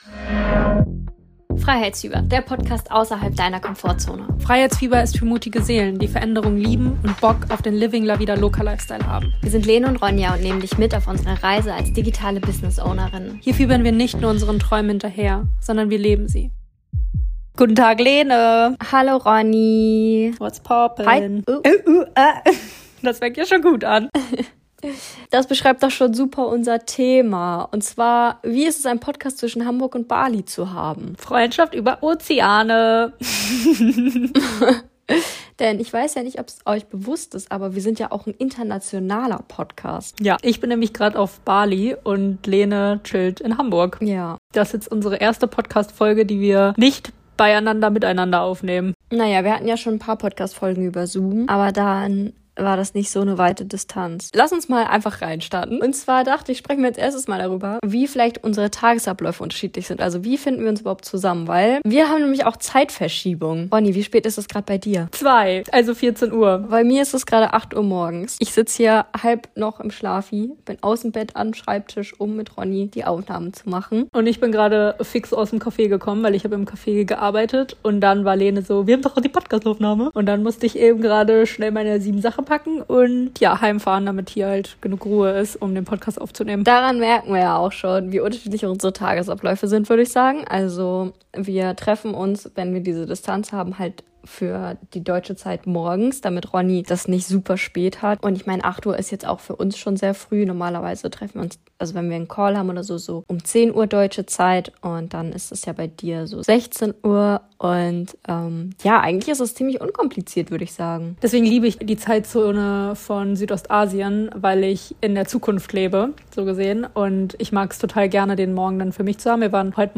Beschreibung vor 1 Jahr Zwei Mikrofone, zwei Kontinente, eine gemeinsame Vision: In dieser Folge nehmen wir dich mit hinter die Kulissen unseres internationalen Podcasts – zwischen Hamburger Frühling und balinesischem Sonnenschein. Was macht diese Entfernung mit unserer Freundschaft, der Kreativität und unserer gemeinsamen Arbeit? Und was braucht es wirklich, damit Verbindung auf Distanz funktioniert?